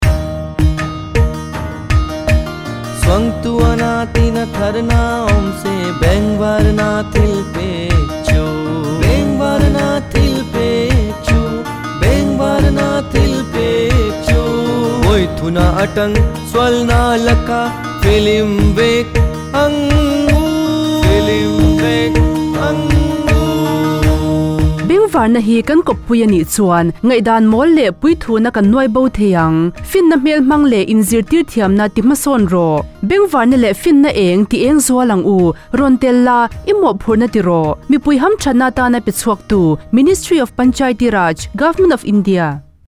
164 Fundamental Duty 8th Fundamental Duty Develop scientific temper Radio Jingle Mizo